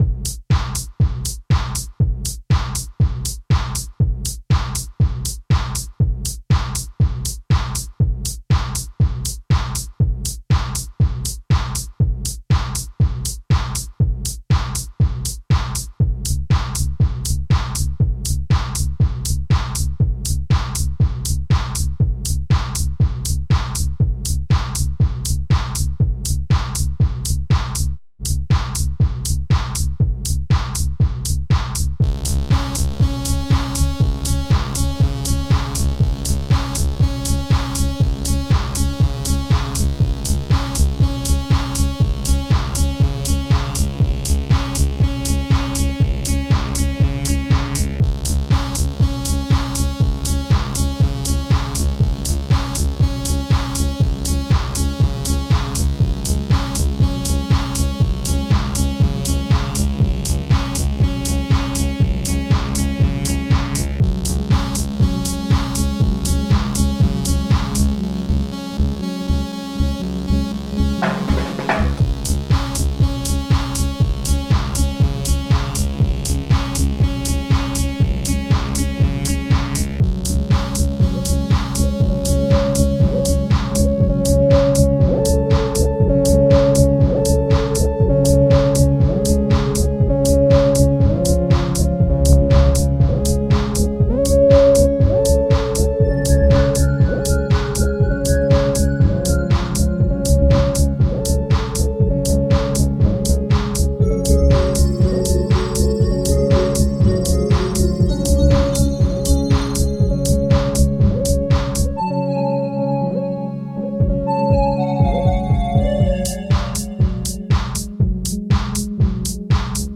Made this one for a theater piece. It was made on the computer (not sure which programs I used back then) with the lead coming from a Technics KN2000.